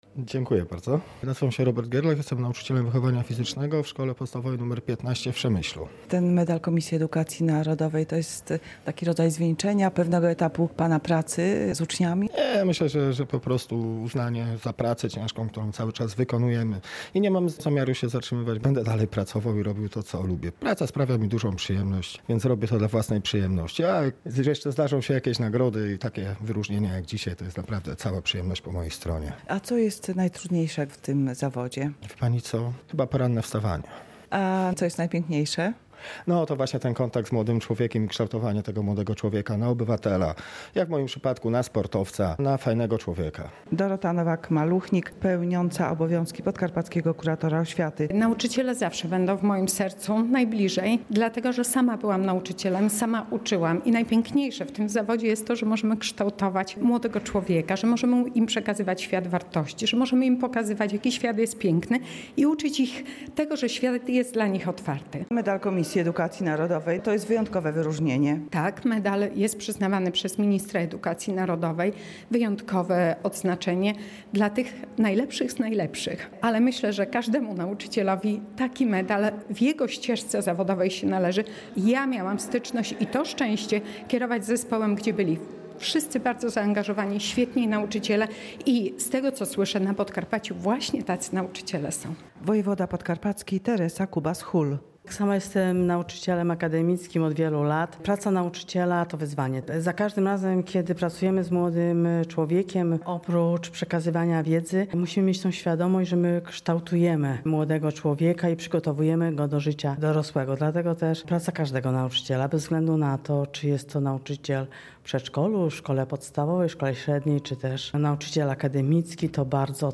Medale otrzymało 41 podkarpackich nauczycieli. Uroczystość odbyła się w Podkarpackim Urzędzie Wojewódzkim.
Jak mówili Polskiemu Radiu Rzeszów nagrodzeni pedagodzy, każde wyróżnienie cieszy, ale medal Komisji Edukacji Narodowej traktują jako docenienie ich codziennej pracy z uczniami.